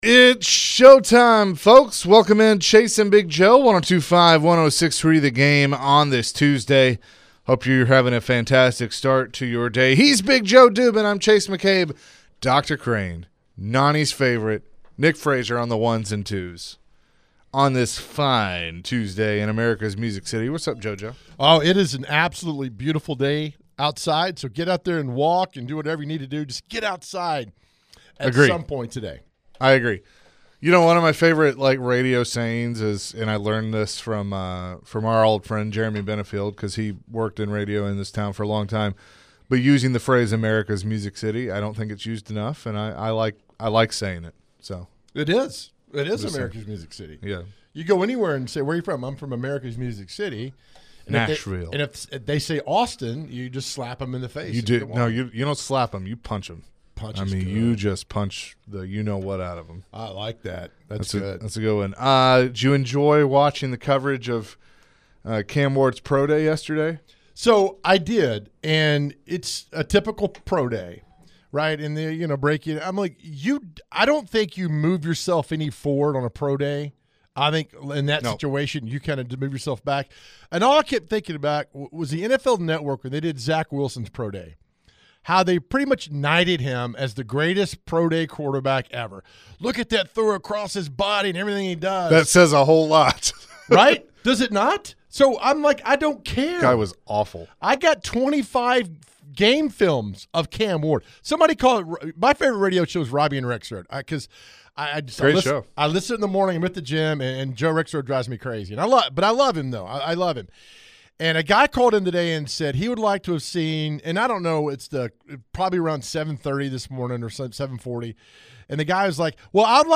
To end the hour, the guys answered some calls and texts about their question of the day.